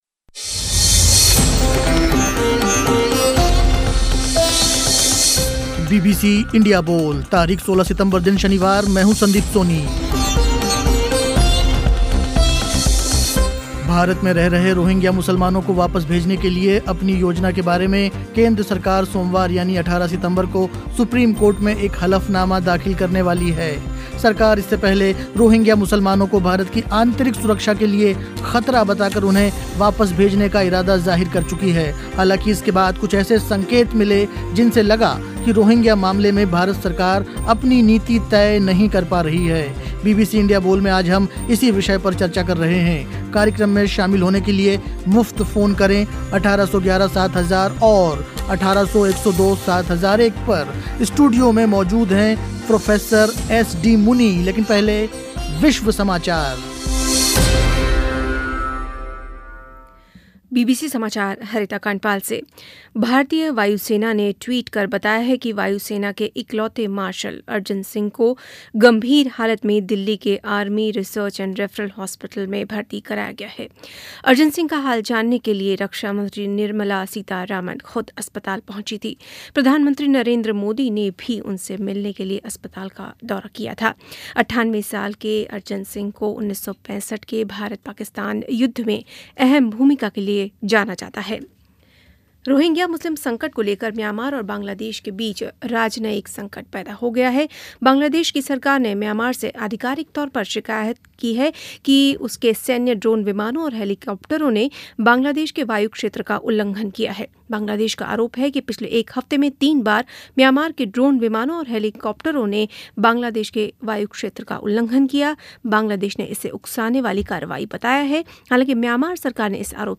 चर्चा हुई रोहिंग्या शरणार्थियों के बारे में भारत की नीति पर. स्टूडियो में